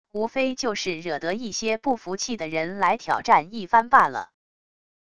无非就是惹得一些不服气的人来挑战一番罢了wav音频生成系统WAV Audio Player